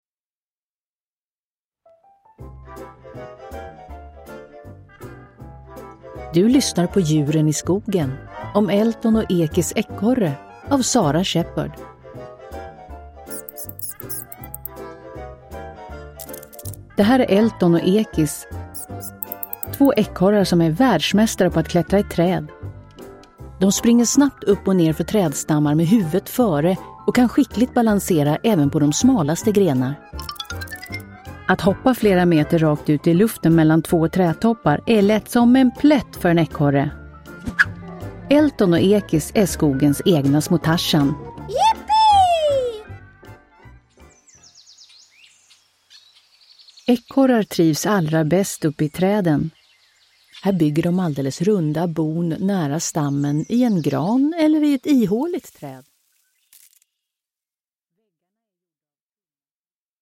Elton och Ekis Ekorre – Ljudbok – Laddas ner